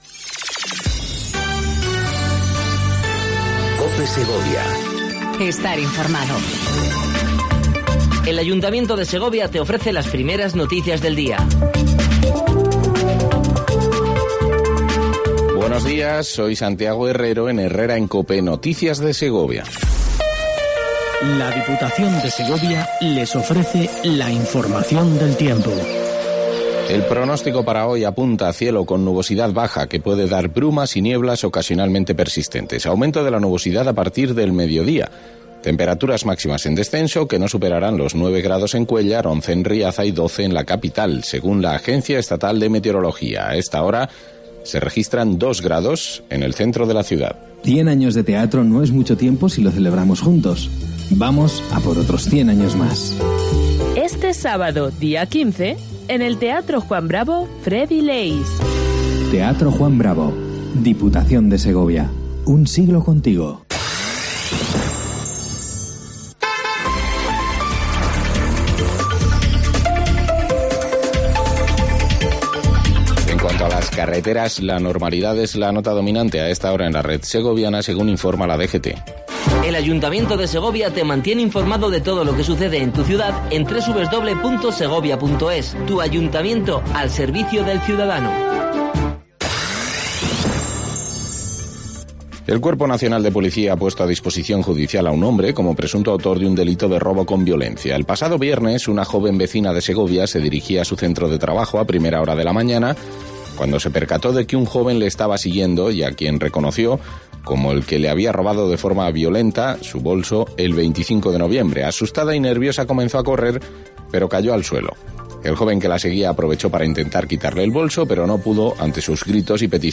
INFORMATIVO 07:55 COPE SEGOVIA 12/12/18
AUDIO: Primer informativo local en cope segovia